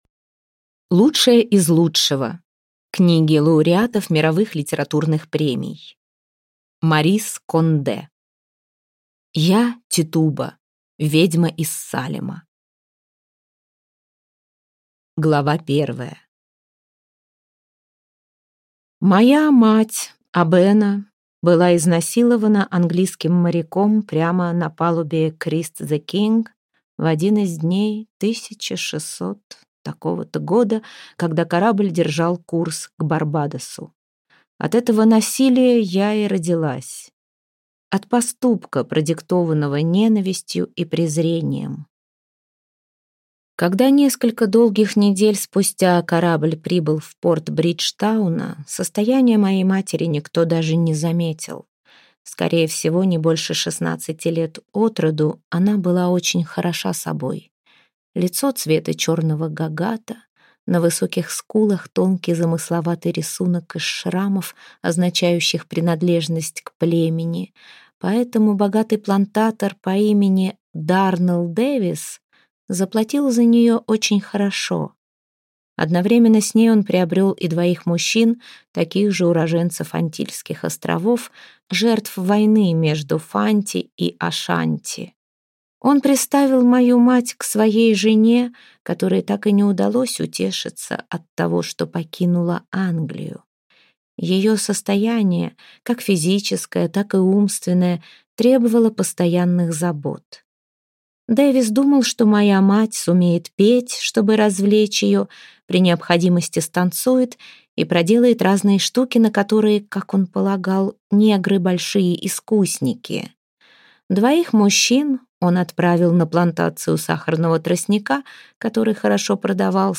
Аудиокнига Я, Титуба, ведьма из Салема | Библиотека аудиокниг